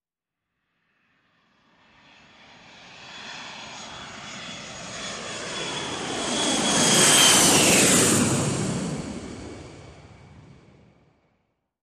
Jet; Large Commercial; Take Off, By with High Airy